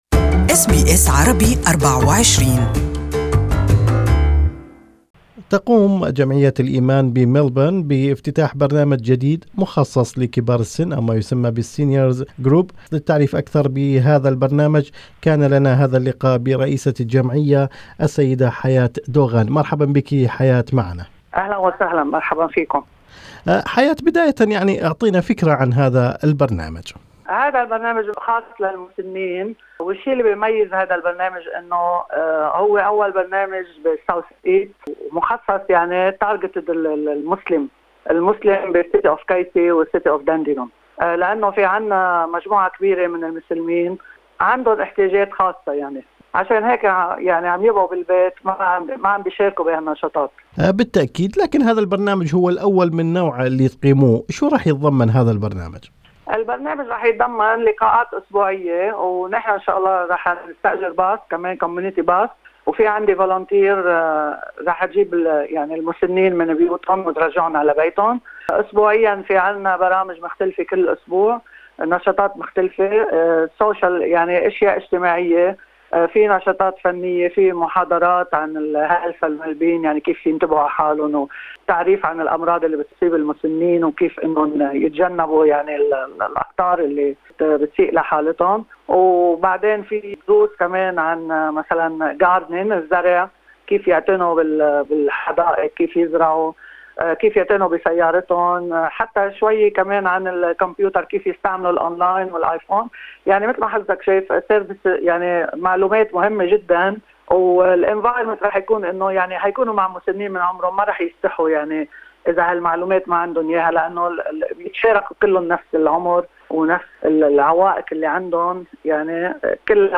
This interview